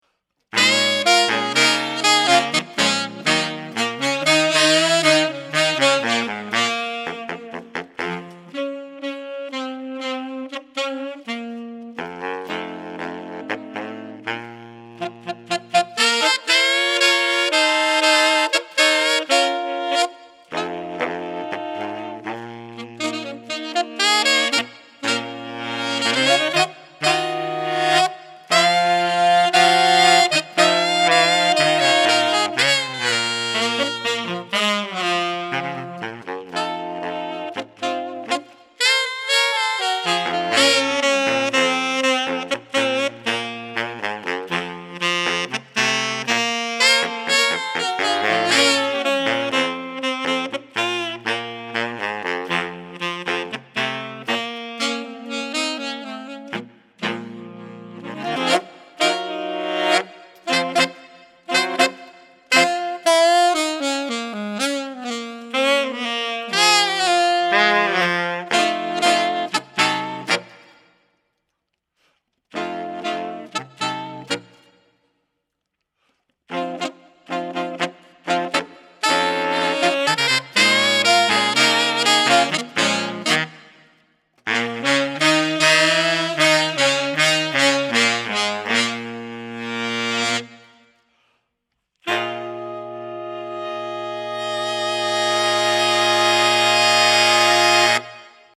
Composer: Negro Spiritual
Voicing: Saxophone Quartet